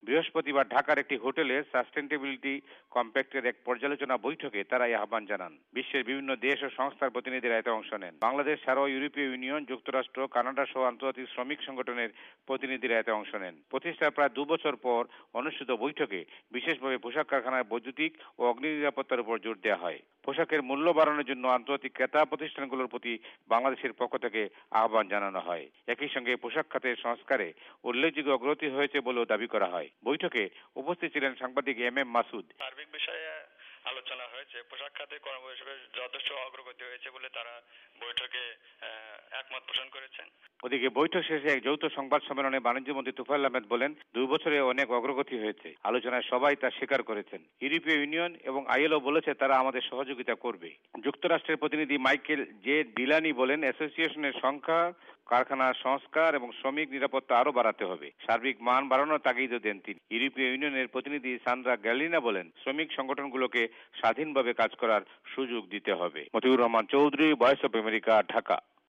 রিপোর্ট পাঠিয়েছেন এর ওপর ঢাকা থেকে